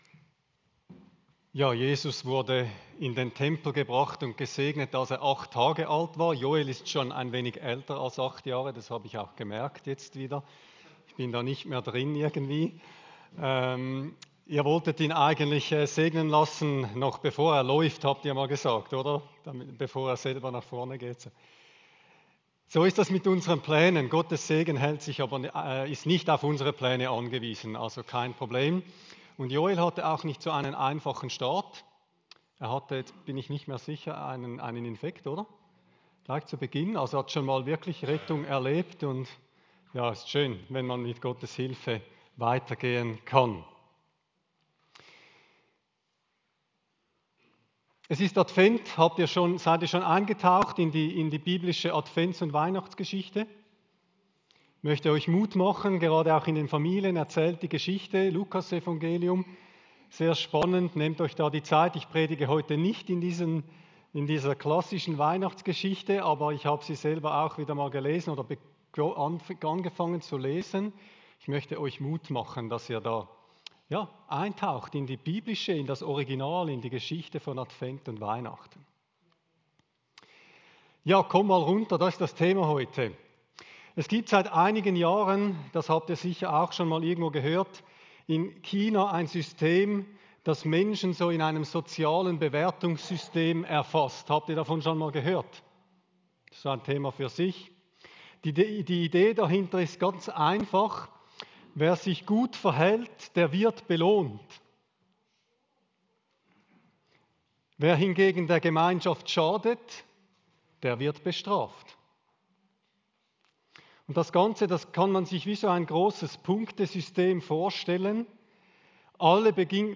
Predigt-7.12.25.mp3